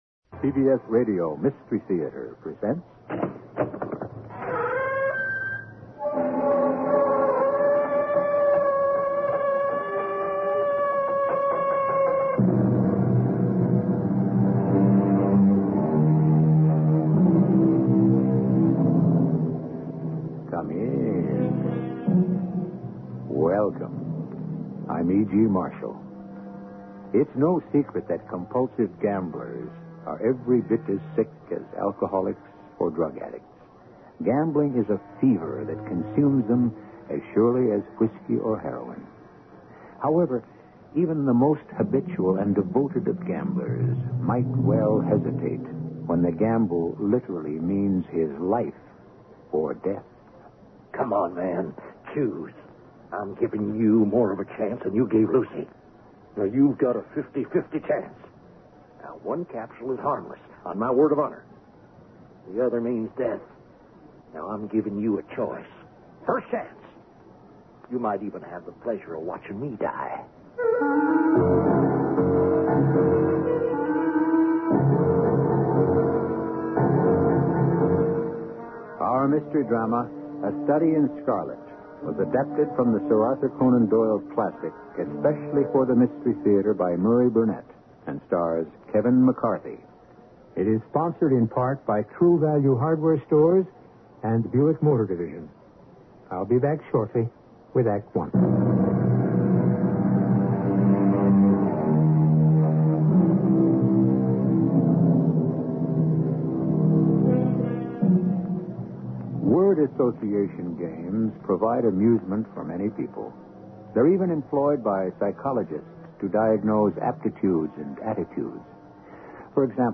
Radio Show Drama with Sherlock Holmes - A Study In Scarlet 1977